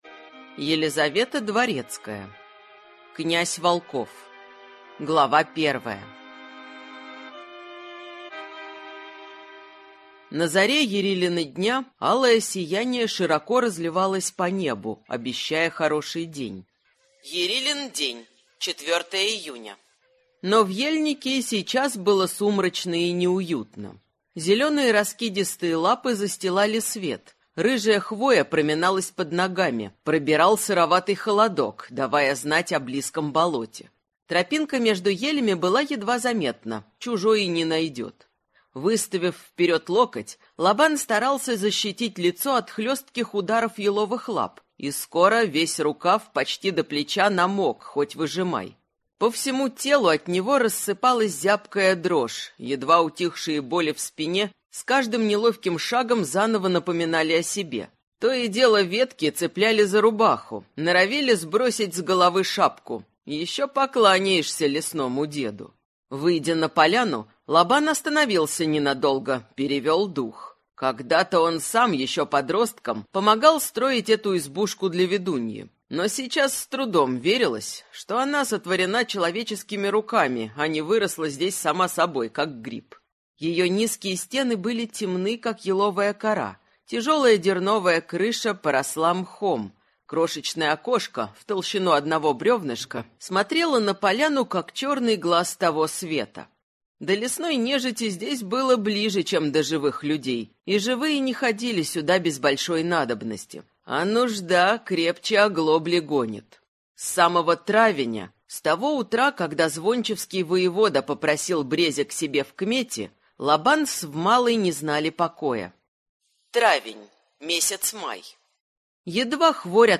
Аудиокнига Огненный волк. Книга 2: Князь волков | Библиотека аудиокниг